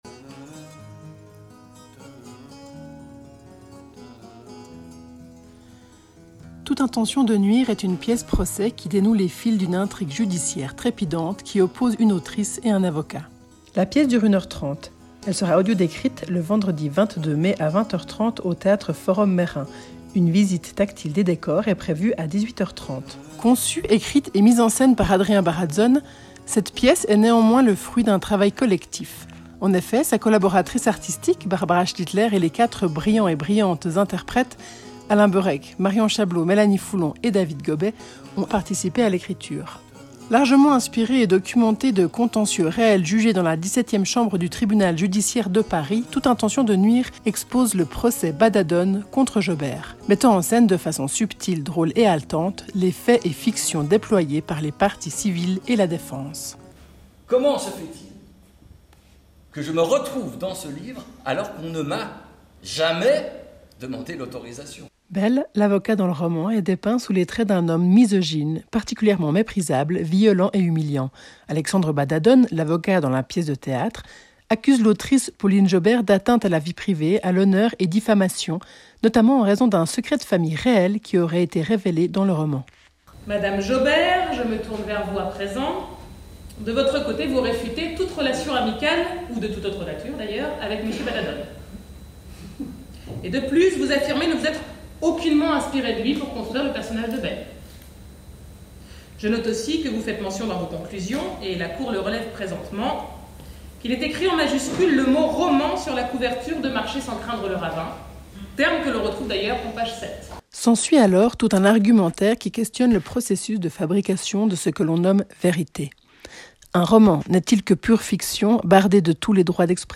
Audiodescription
Bande annonce